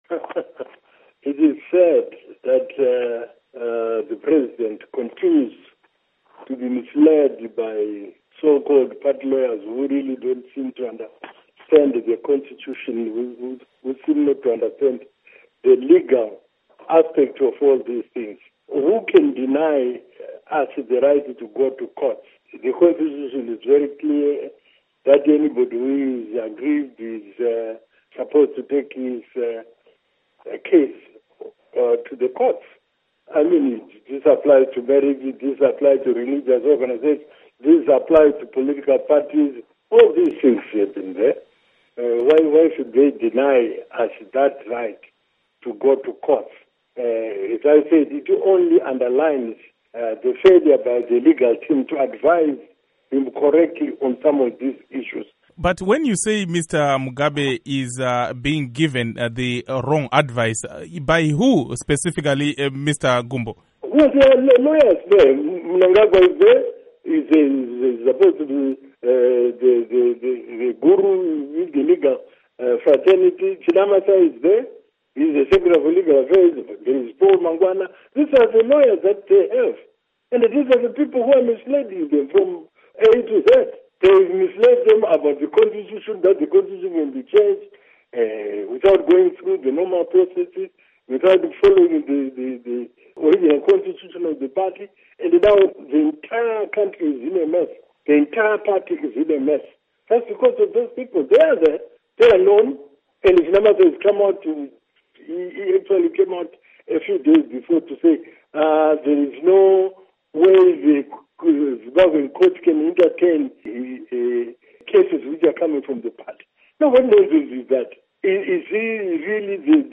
Ingxoxo Esiyenze LoMnu. Rugare Gumbo